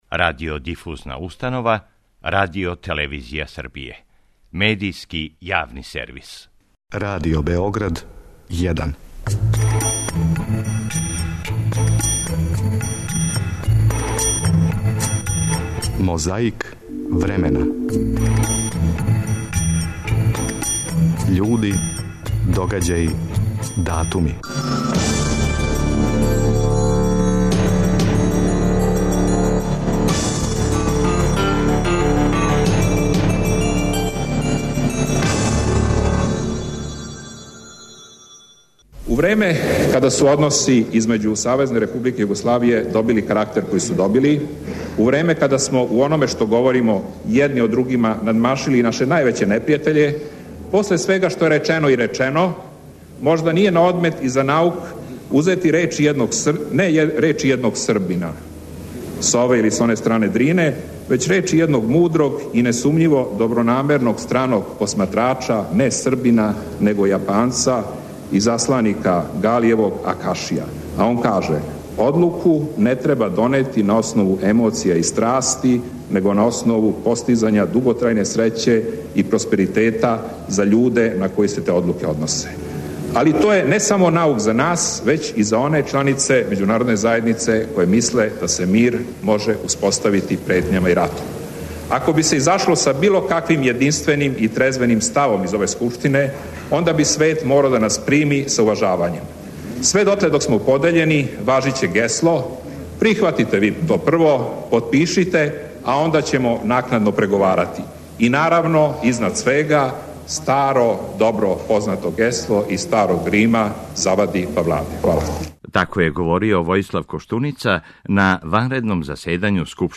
Тако је говорио Војислав Коштуница на ванредном заседању Скупштине Србије, 25. августа 1994.
Подсећа на прошлост (културну, историјску, политичку, спортску и сваку другу) уз помоћ материјала из Тонског архива, Документације и библиотеке Радио Београда.